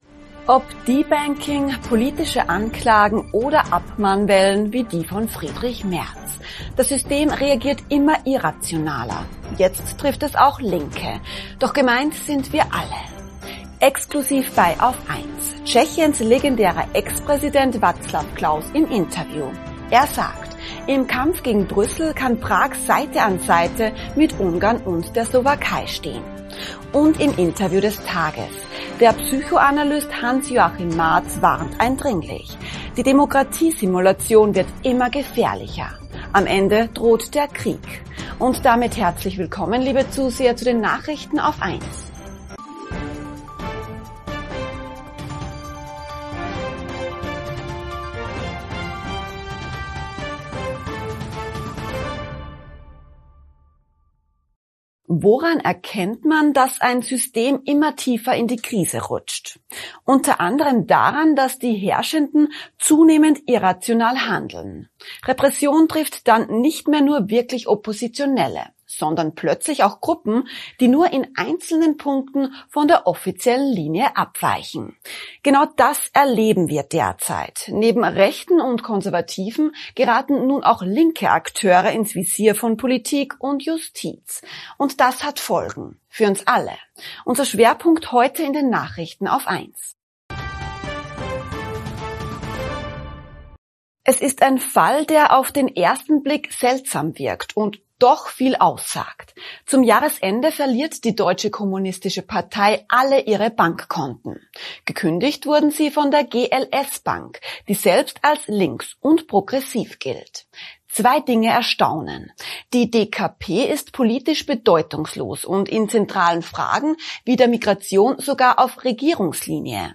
+ Exklusiv bei AUF1: Tschechiens legendärer Ex-Präsident Václav Klaus im Interview. Er sagt: Im Kampf gegen Brüssel kann Prag Seite an Seite mit Ungarn und der Slowakei stehen.